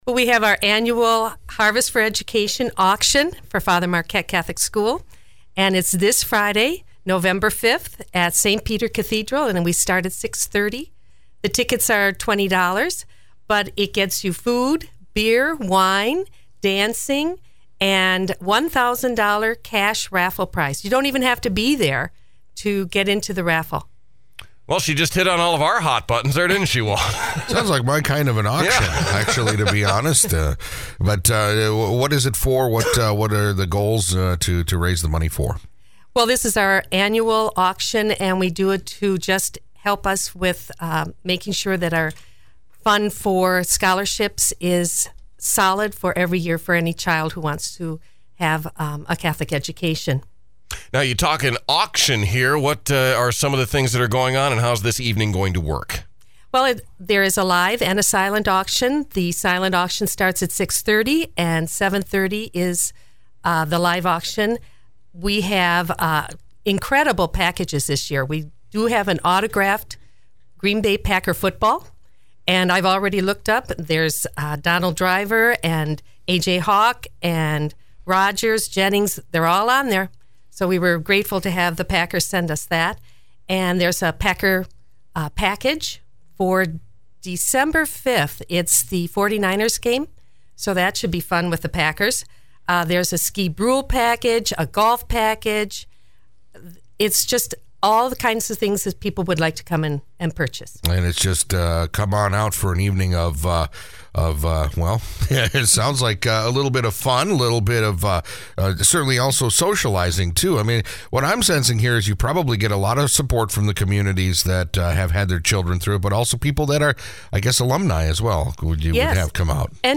NewsOld Interviews Archive